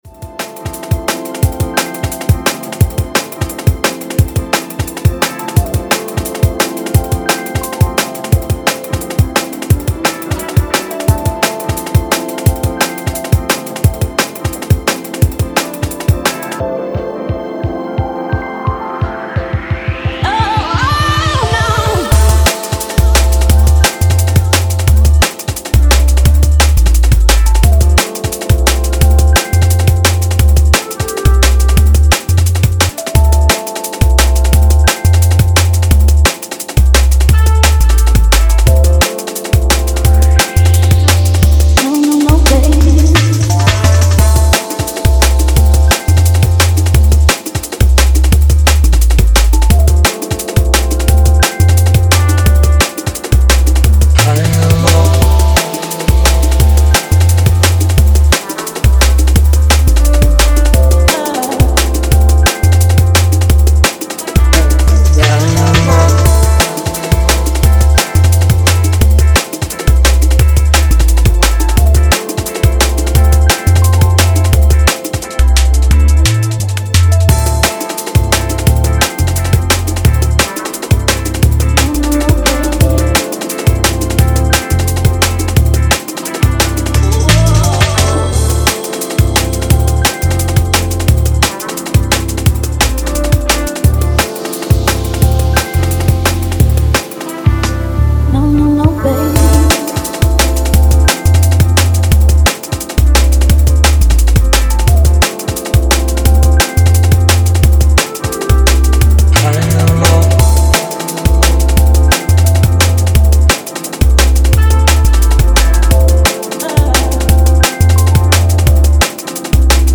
We’ve got two tracks absolutely drenched in soul and funk